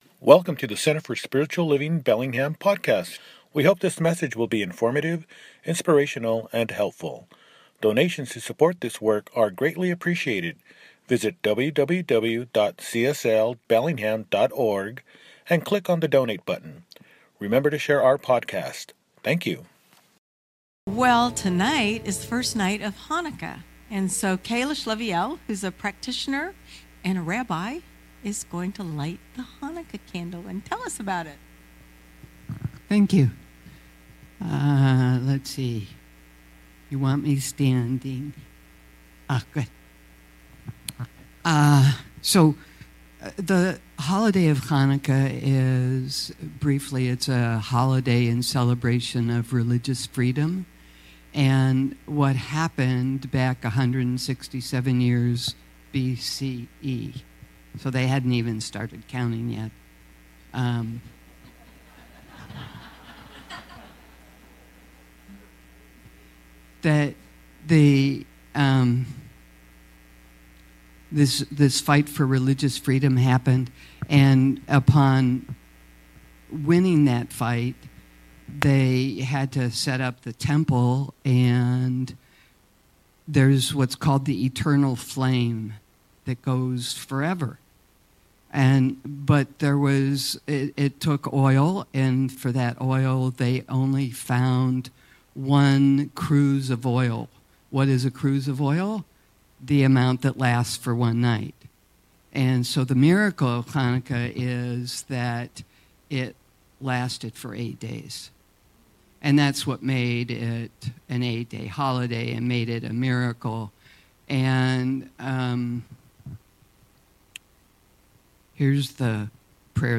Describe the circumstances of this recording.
From Crackly to Supple – Celebration Service | Center for Spiritual Living Bellingham